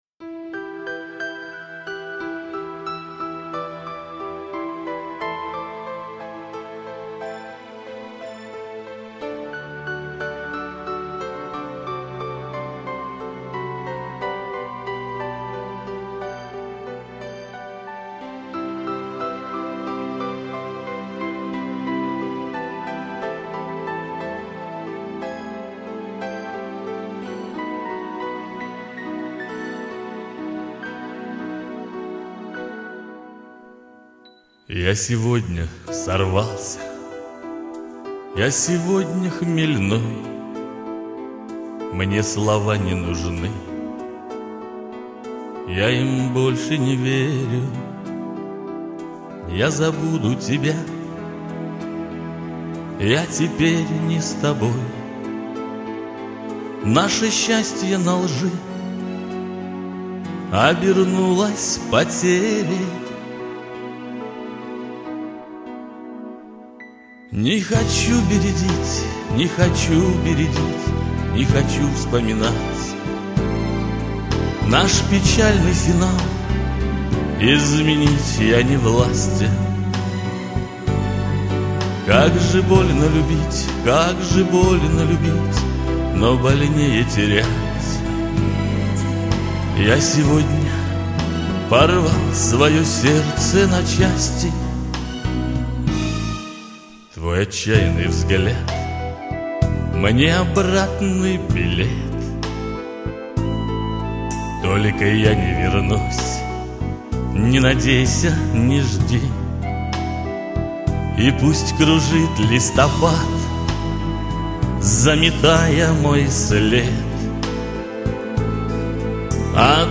Небольшая подборка прекрасного шансонье...